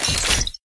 mech_bo_reload_01.ogg